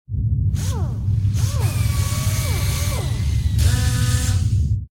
repair2.ogg